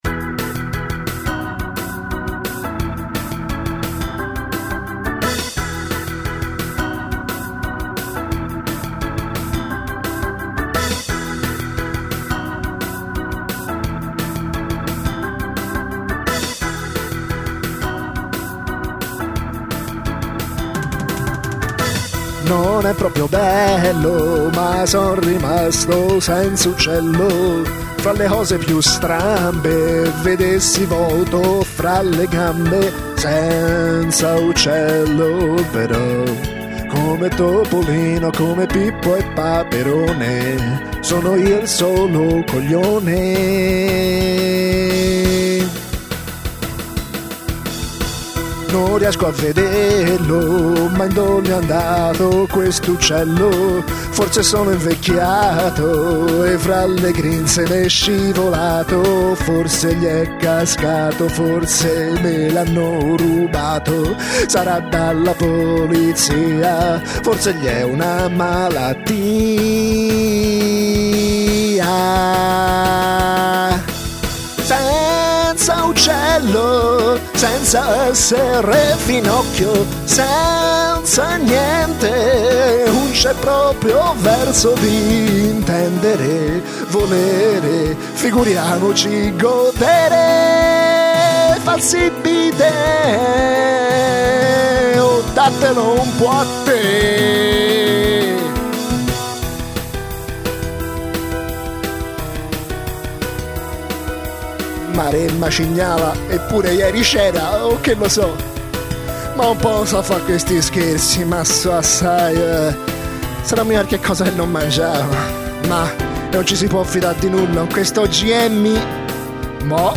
Questa e' la cover